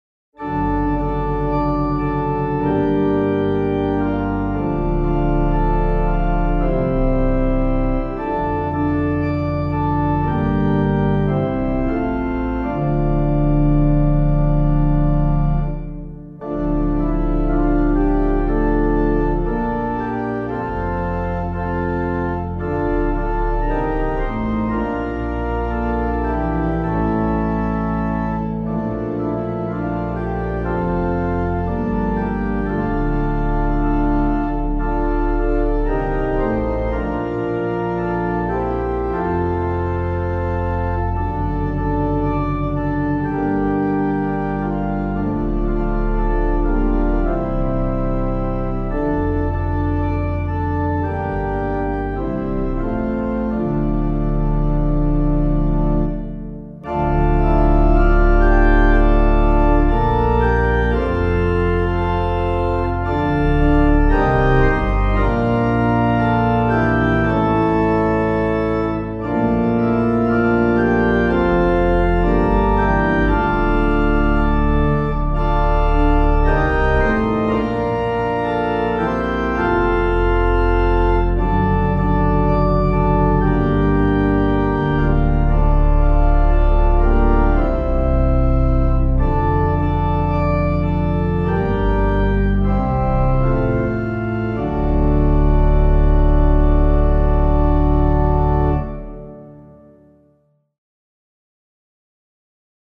Composer:    French carol, 17th cent.;
organ